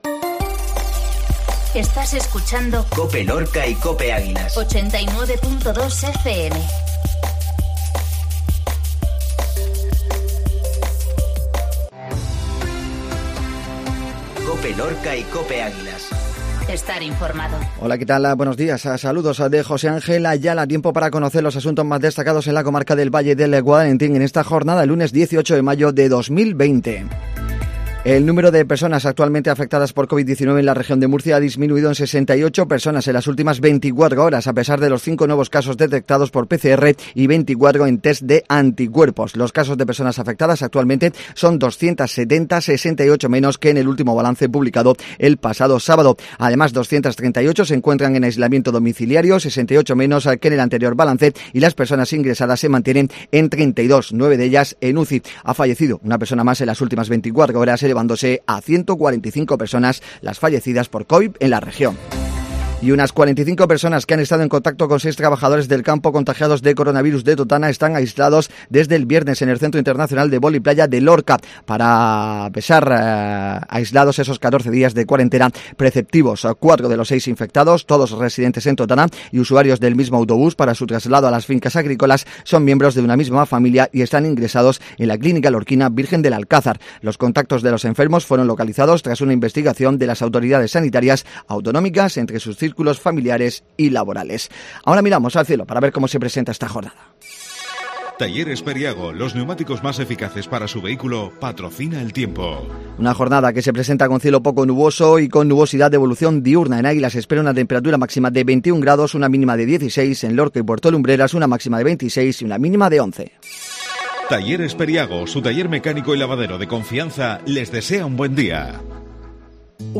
INFORMATIVO MATINAL LUNES